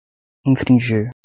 Pronúnciase como (IPA) /ĩ.fɾĩˈʒi(ʁ)/